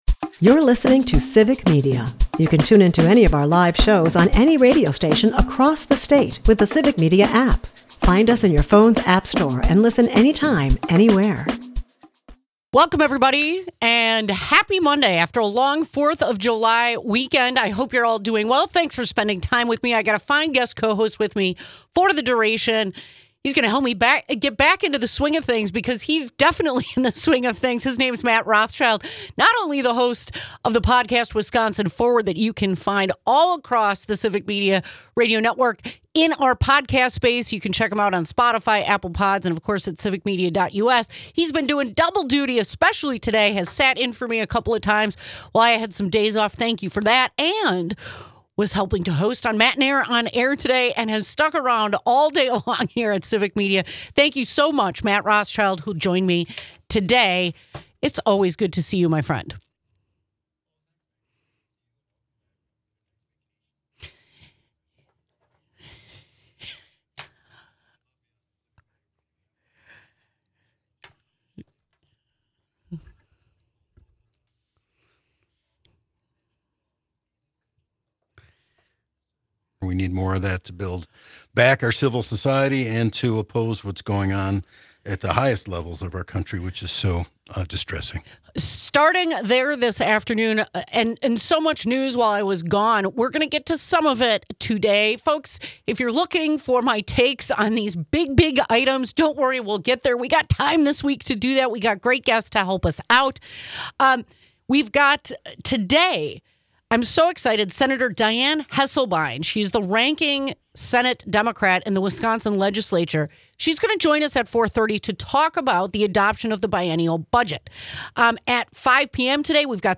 With the biennial state budget now signed, State Senator Dianne Hesselbein joins to highlight key Democratic wins: increased funding for special education, vital support for the UW system, and critical relief for childcare providers.